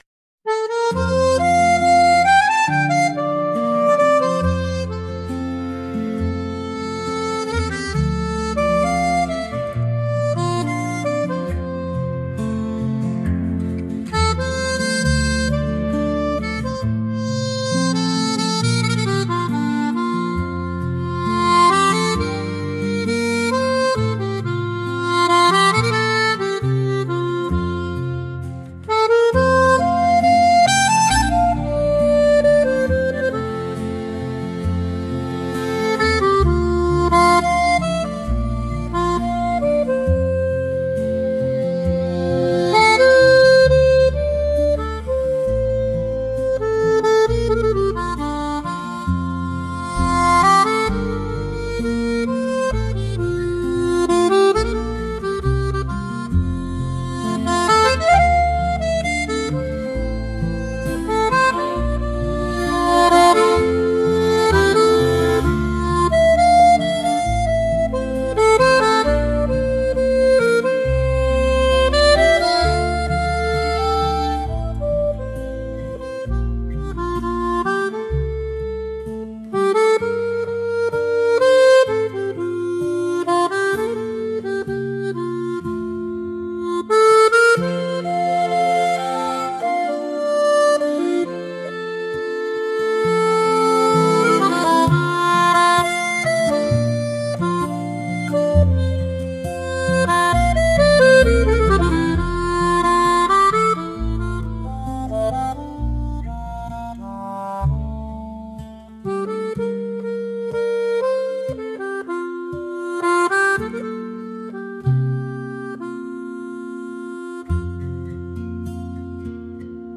At its heart, The Flying Dutchman is a single voice on stage — intimate, unreliable, funny, furious, and tender.
The score blends contemporary musical theatre with folk, shanty echoes, and modern storytelling.